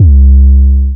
TM88 Fuel808.wav